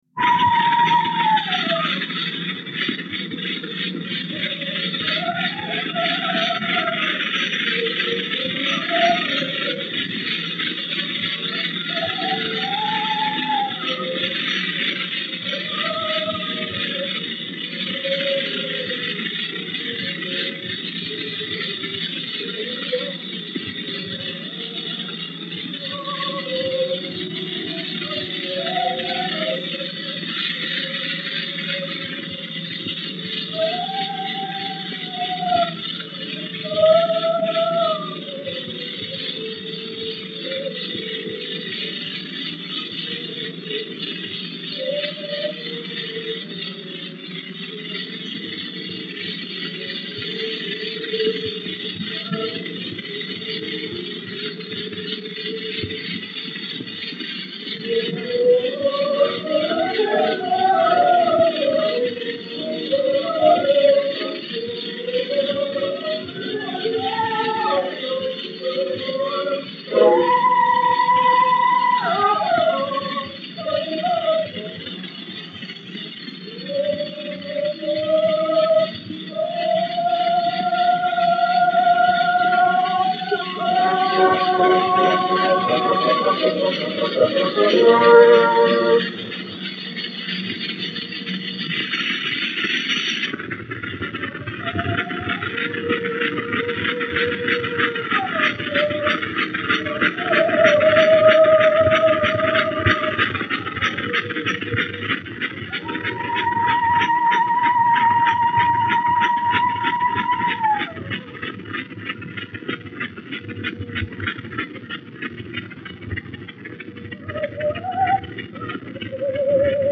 enregistré en public dans ce théâtre le 15 mars 1901 sur un cylindre par Lionel Mapleson (son très défectueux)
Breval Reszke - Africaine - Mapleson.mp3